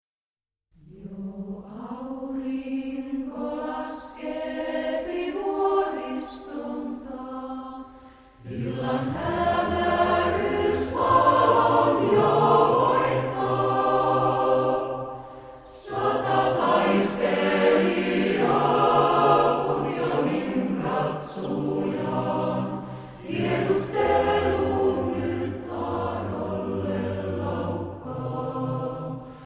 Vanha venäl. sävelmä
Soloist Singer